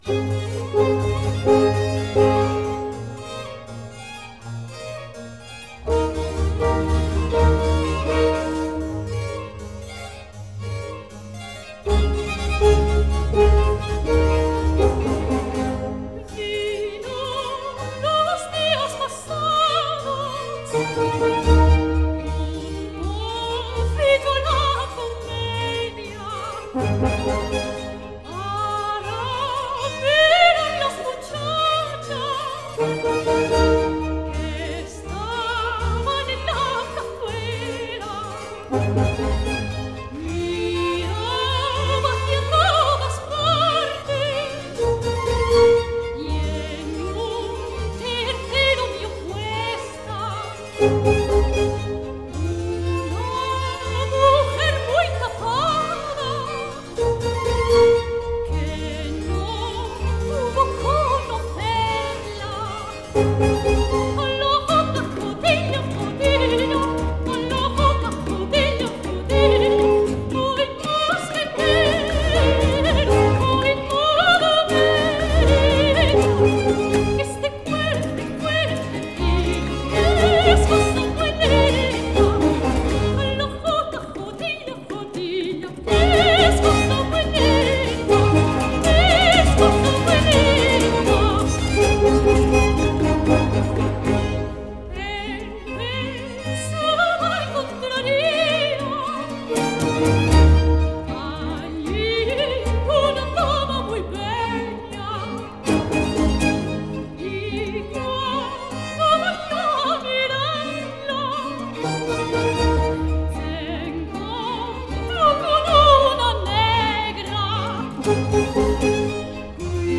soprano
7_la_sale_mi_guitarra_no__2_coplas_allegro.mp3